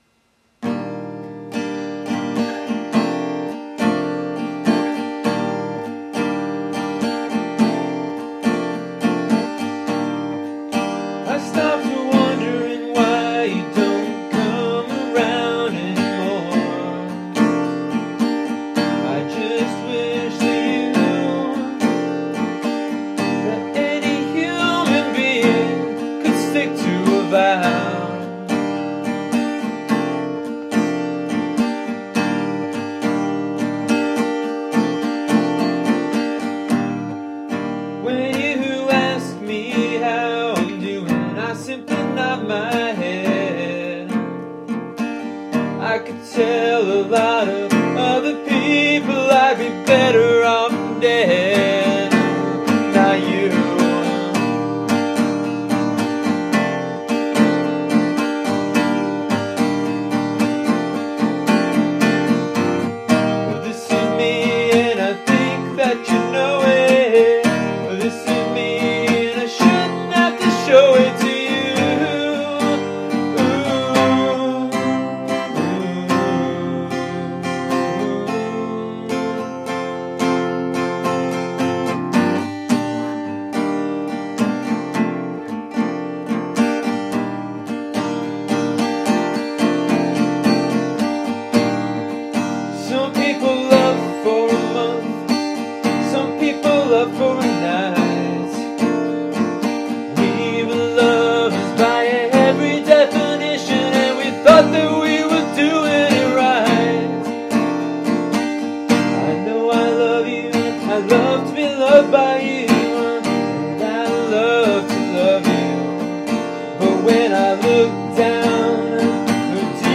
Going into the session, I thought that the “studio recording” of “Vows and Changes” would be very stripped down and acoustic, not so different from the demo I recorded a few months ago.
11-vows-and-changes-acoustic-demo.mp3